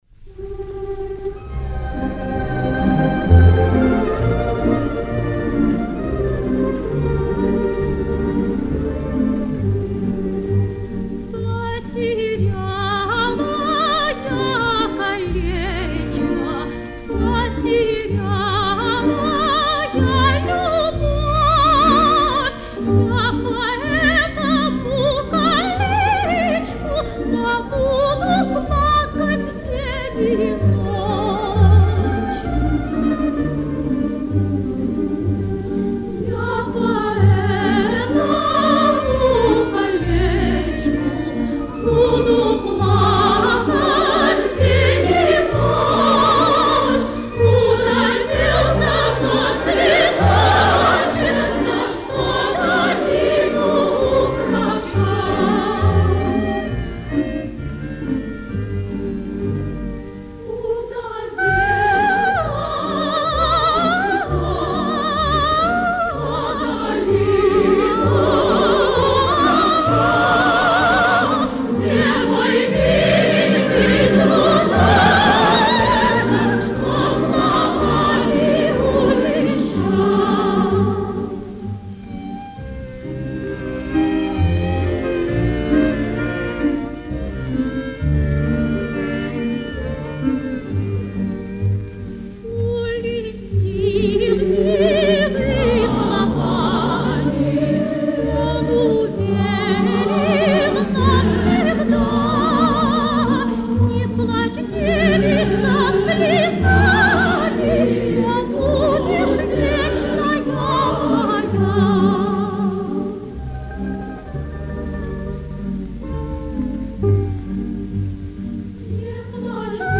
Удивительно, но и эта народная песня помогала бить врагов!
Отличное, правда, послевоенное исполнение.